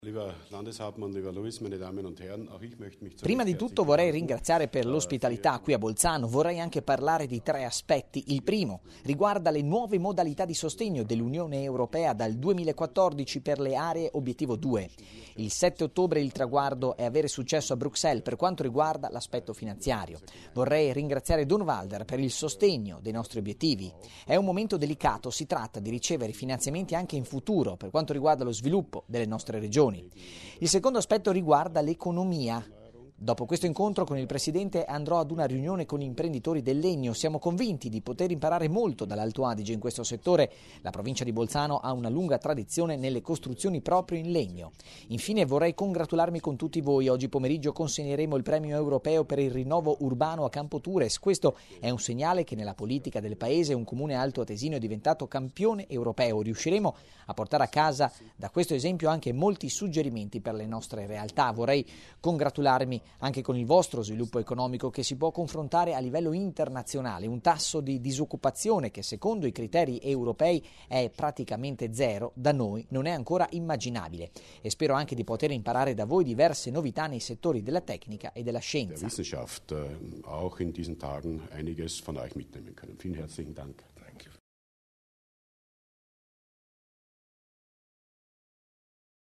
Il Presidente Durnwalder sui temi trattati
Politica | 24.09.2010 | 12:37 Durnwalder incontra il governatore austriaco Pröll: lavoro comune per ricerca e finanziamenti UE Collaborazione per progetti transfrontalieri nel settore della ricerca e dell’innovazione, impegno comune a Bruxelles per i finanziamenti allo spazio rurale: sono alcuni dei temi affrontati dal presidente della Provincia Luis Durnwalder oggi (24 settembre) a Bolzano nell’incontro con il governatore dell’Austria inferiore Erwin Pröll.